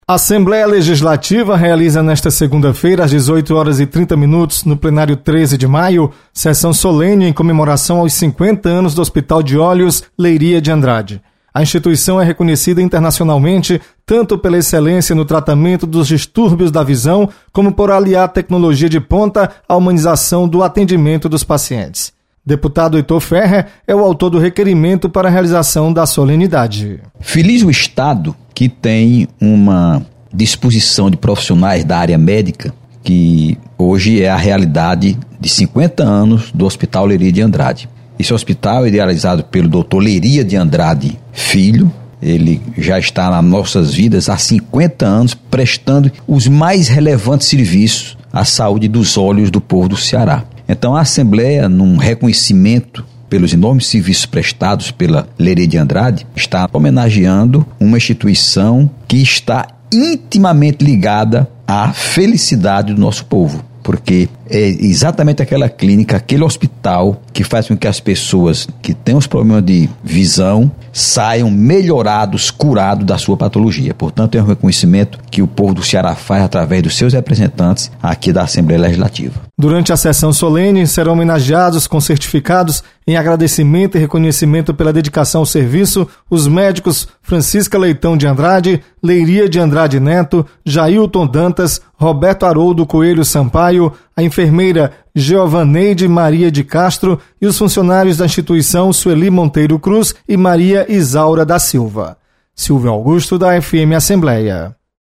Você está aqui: Início Comunicação Rádio FM Assembleia Notícias Sessão Solene